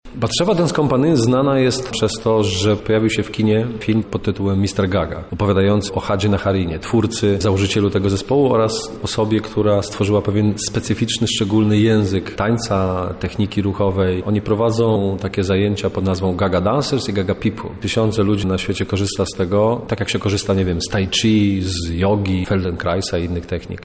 Po spektaklu zapytaliśmy uczestników wydarzenia o ich osobisty odbiór i wrażenia.